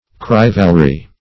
Corivalry \Co*ri"val*ry\